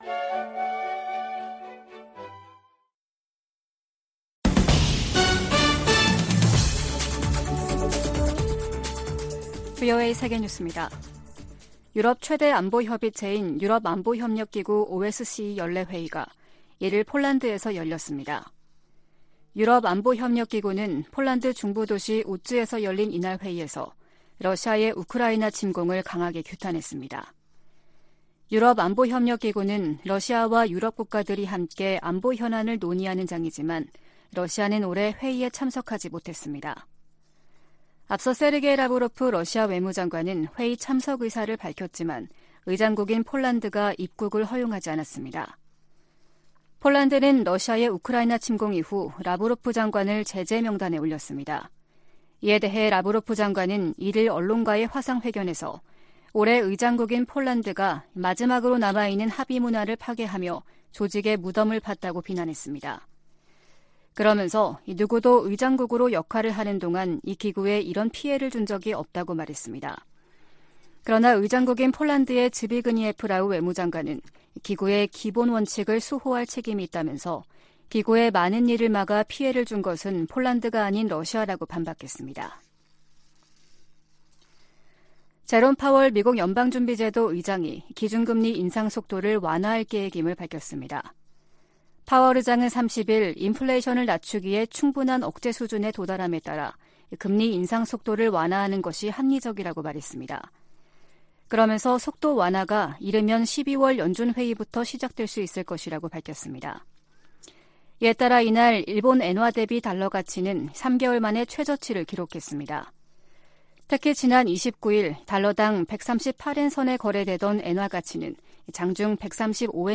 VOA 한국어 아침 뉴스 프로그램 '워싱턴 뉴스 광장' 2022년 12월 2일 방송입니다. 바이든 행정부는 한일 동맹과의 긴밀한 공조 속에 한반도의 완전한 비핵화를 계속 추진할 것이라고 제이크 설리번 백악관 국가안보보좌관이 밝혔습니다. 북한과 중국, 러시아, 이란, 시리아 지도자들은 국내 산적한 문제에도 불구하고 국제사회에 위협을 가하는 ‘불량배와 독재자’라고 미치 맥코넬 상원 공화당 원내대표가 비난했습니다.